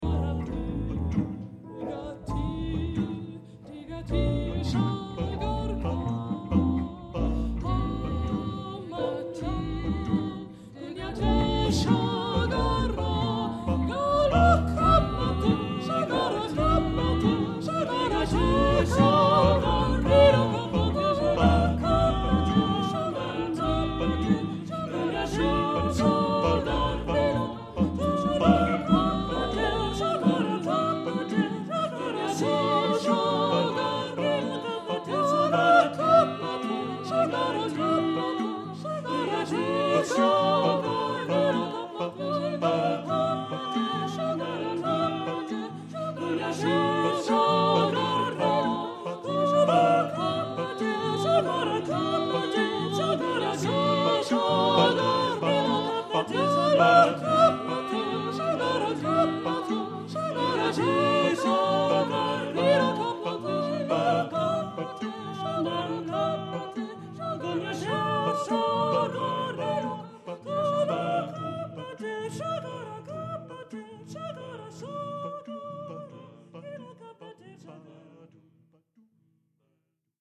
Genre-Stil-Form: Kanon ; Jazz ; Ostinato ; weltlich
Chorgattung:  (8 gleichstimmig Stimmen )
Instrumente: Händeklatschen
Tonart(en): a-moll